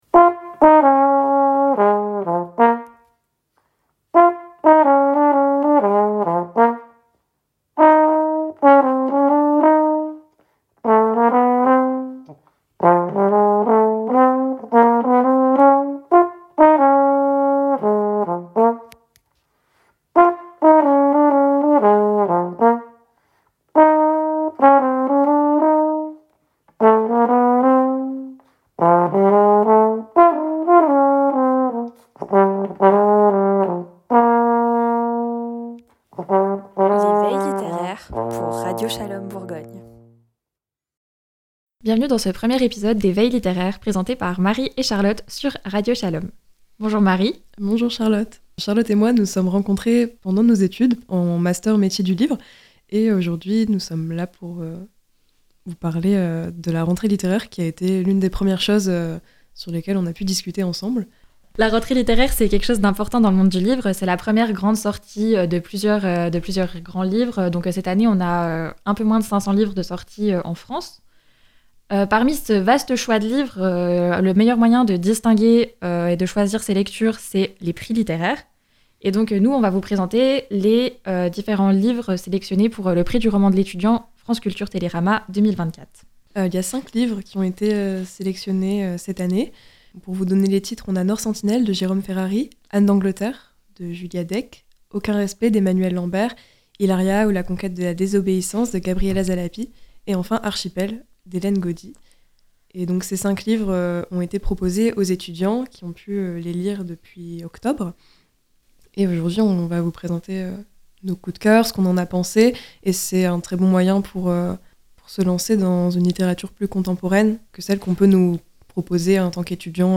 Bienvenue dans cette toute nouvelle émission littéraire.